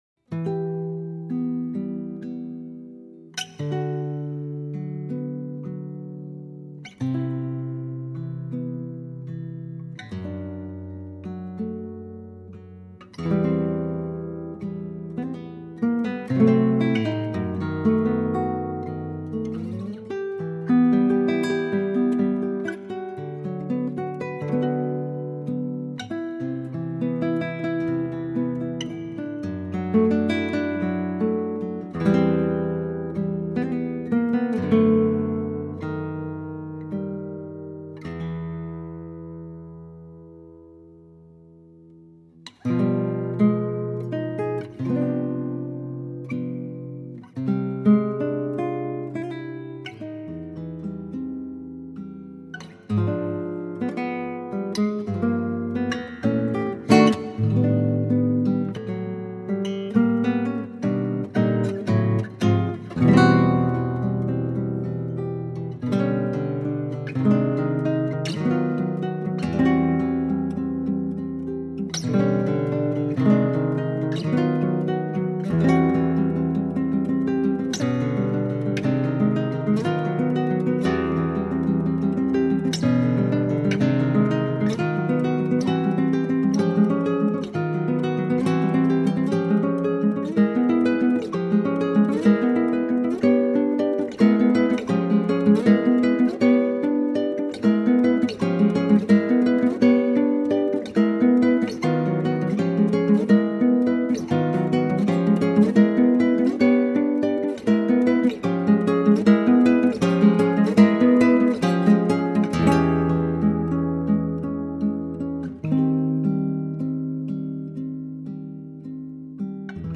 Instrumentação: violão solo
Tonalidade: Em | Gênero: instrumental brasileiro